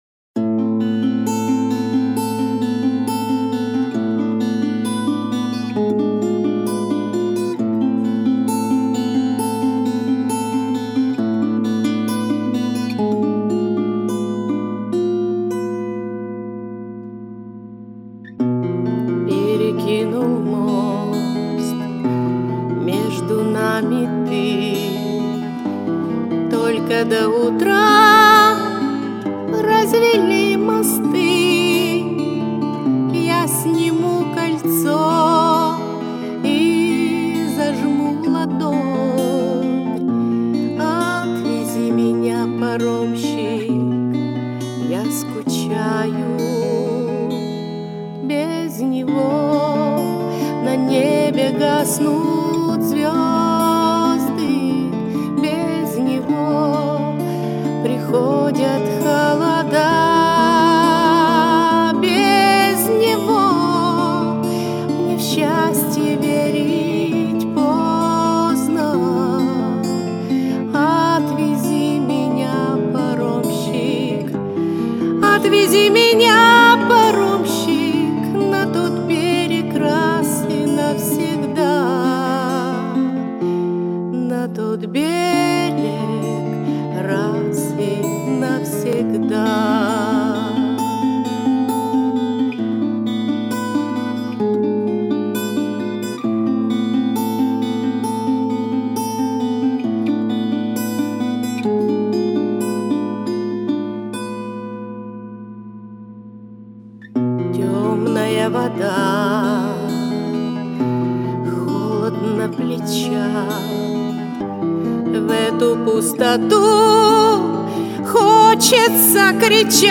Красиво поете,девчата55555